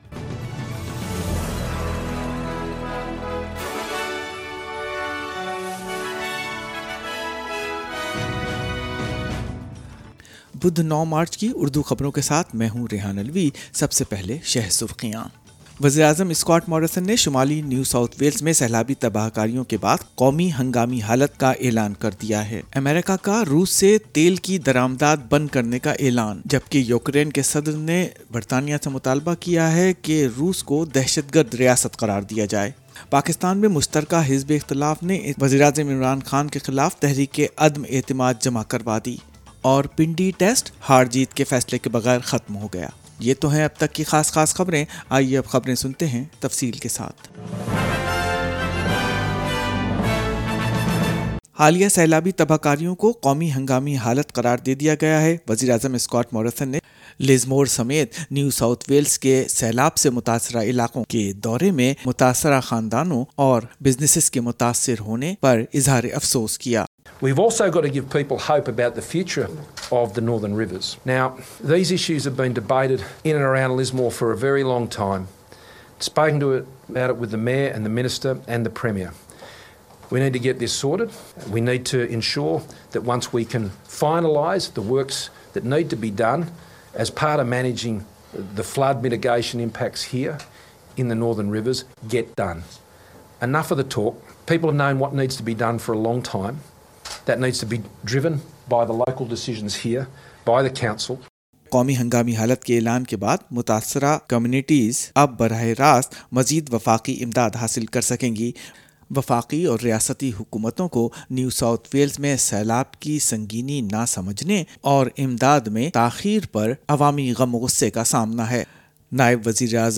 Additional disaster payments for NSW flood victims - urdu News 9 March 2022